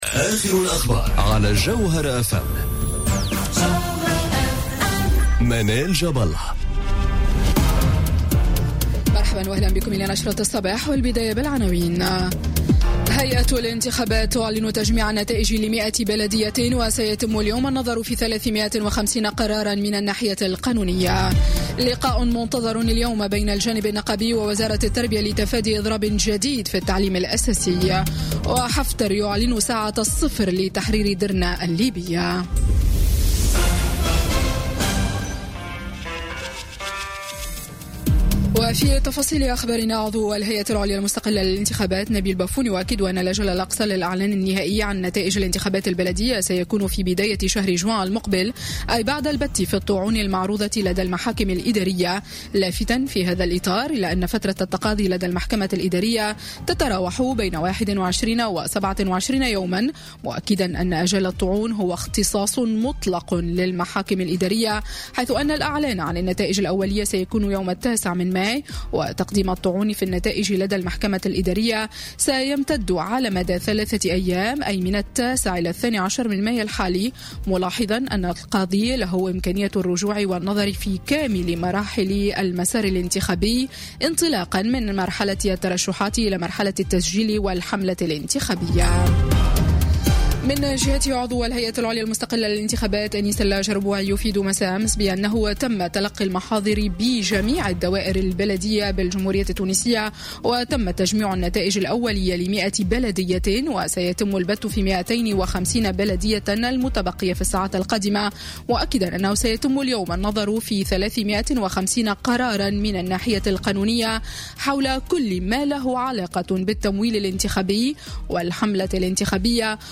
journal info 07h00 du Mardi 8 Mai 2018